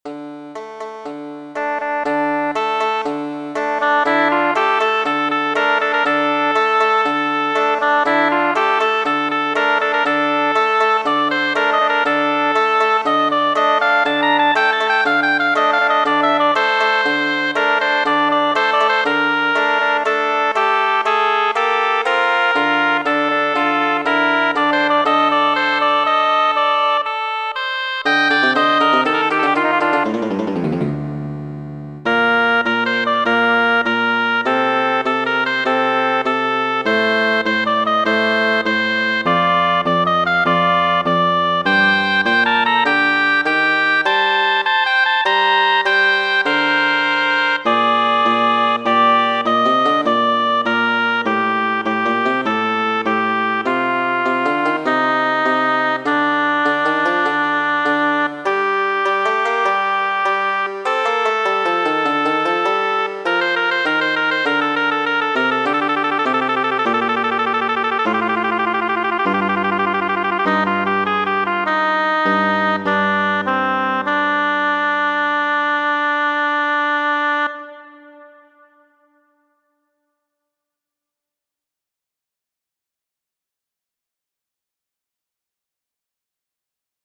Dua araba marŝo! estas mia propra verko, do vi aŭskultas nun la originalan version.